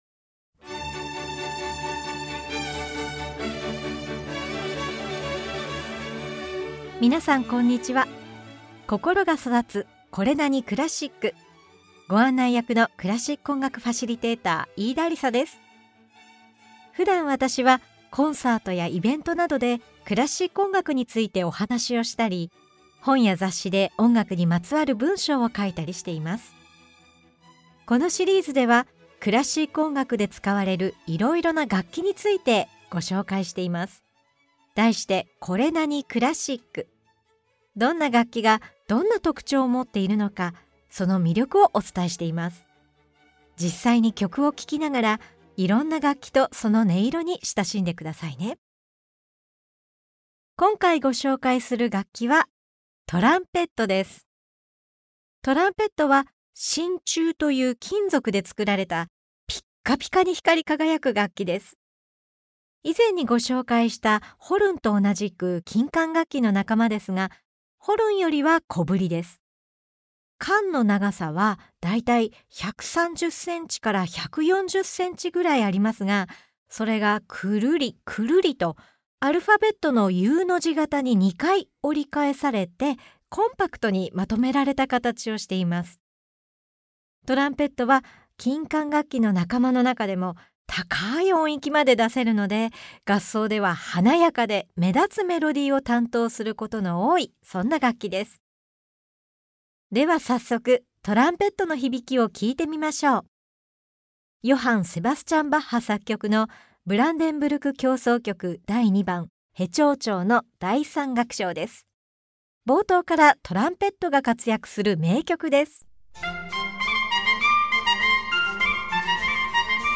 [オーディオブック] 心が育つ これなに？クラシック 楽器大全 Vol.10 〜トランペット〜
実際に曲を聴きながら、いろんな楽器とその音色に親しんでください。
トランペットは、大きく輝かしい音色を響かせることができる一方で温かみがあって柔らかな音も出せる豊かな表現力をもった楽器。トランペットが奏でる印象的なファンファーレから始まるマーラー作曲の交響曲、トランペットが大活躍するムソルグスキーの『展覧会の絵』などを紹介しながら、トランペットのさまざまな音色とその魅力を紹介します！